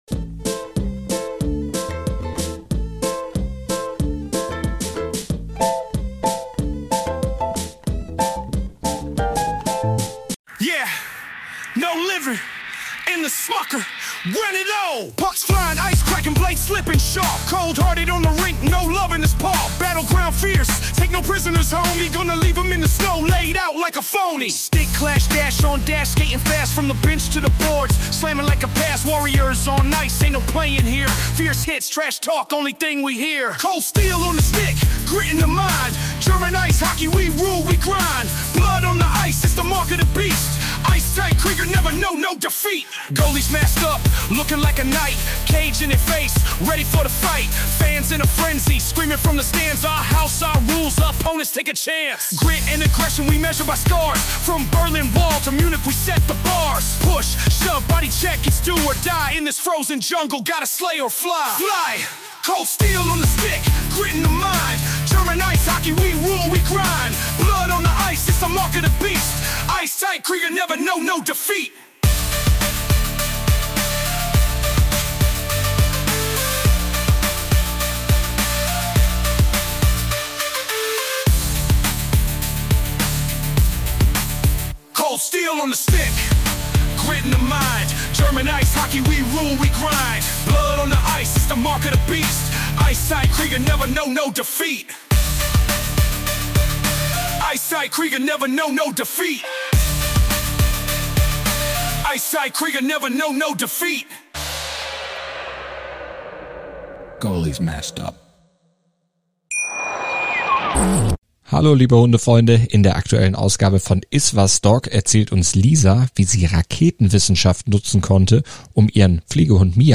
sportverrueckt-74-der-rap-eishockey-song.mp3